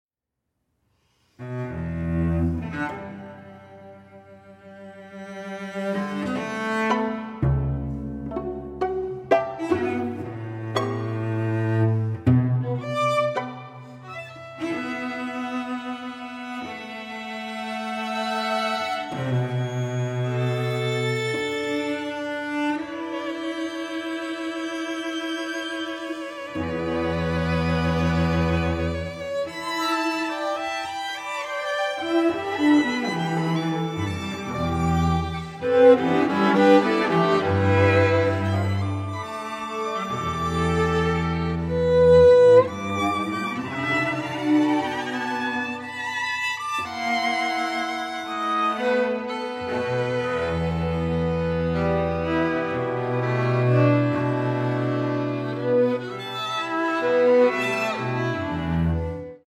violin
viola
cello
Recorded at the Troy Savings Bank Music Hall